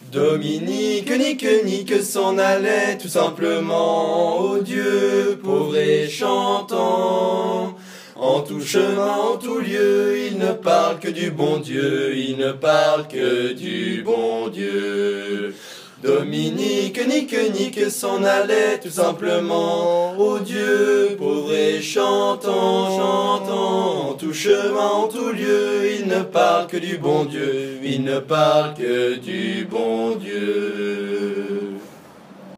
A capella, le retour